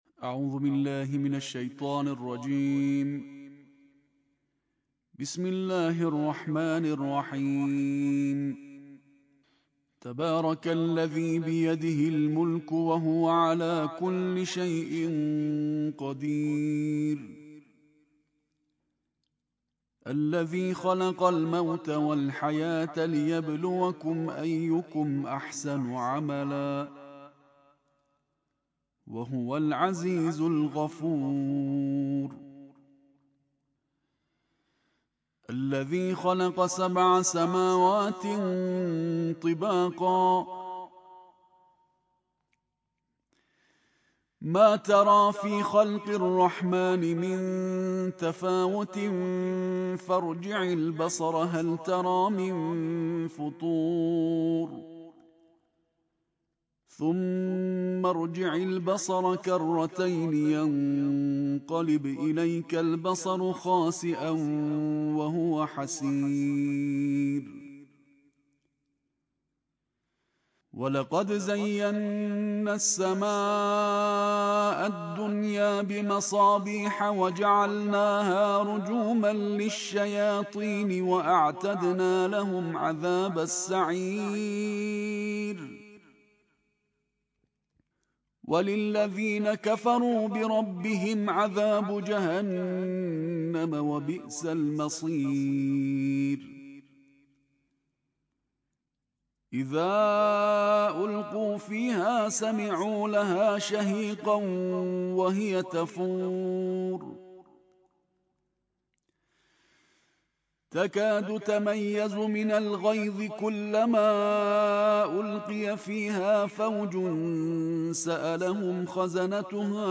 IQNA - Ramazan ayının 29. gününde İranlı seçkin kâri Kur’an-ı Kerim’in 29. cüzünü tertille okudu.